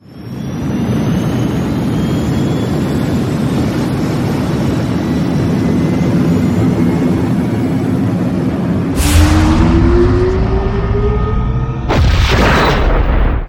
launch2.wav